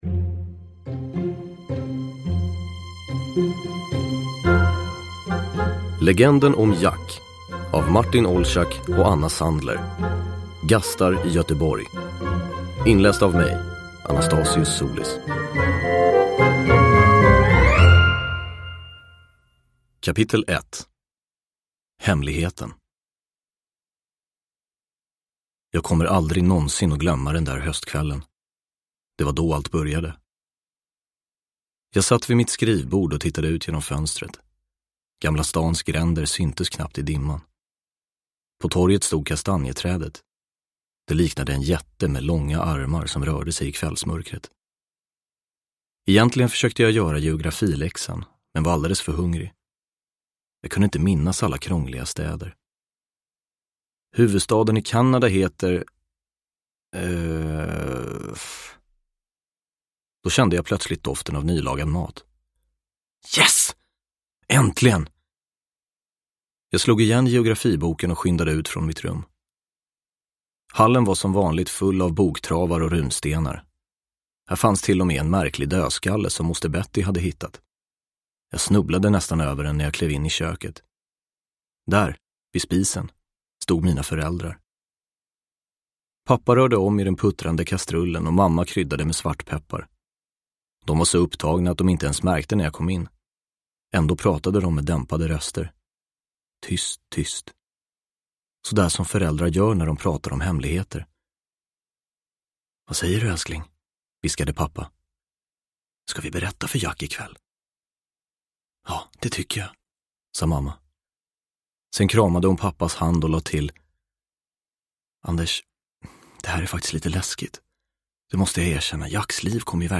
Gastar i Göteborg – Ljudbok – Laddas ner
Uppläsare: Anastasios Soulis